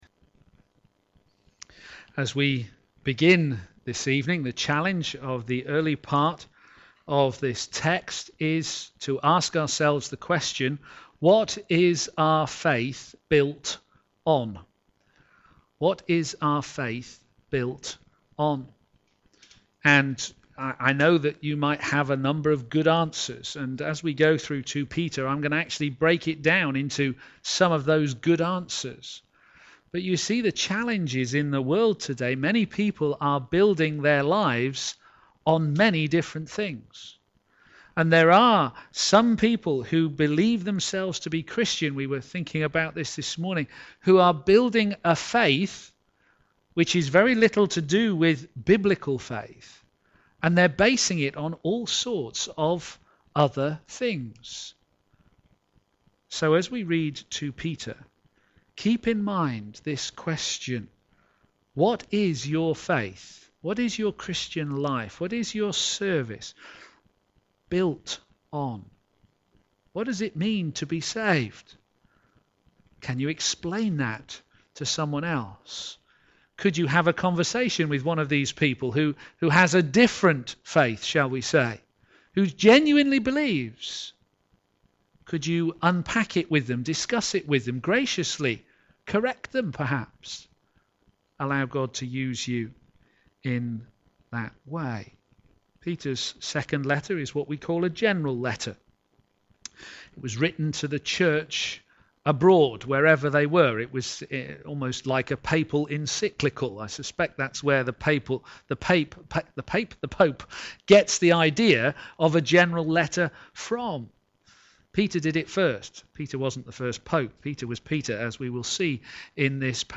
p.m. Service
Know your Salvation - Rooted in His Divine Power Sermon